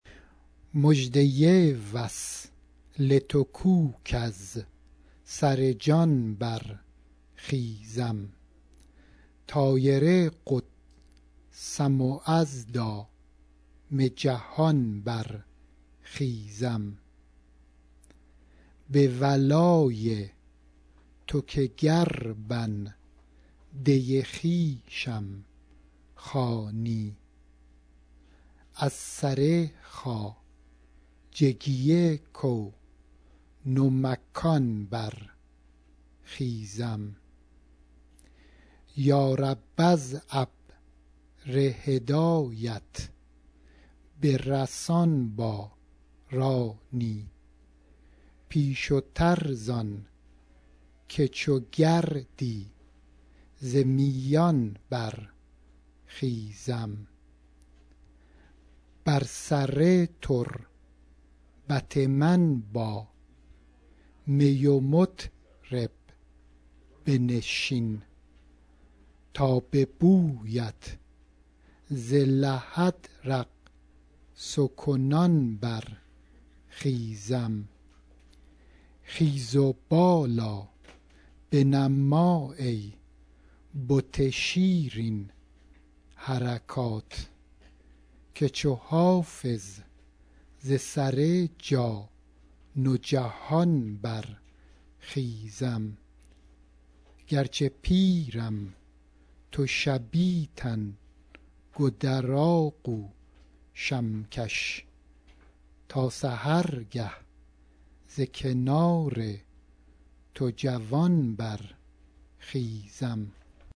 Listen to it read according to the meter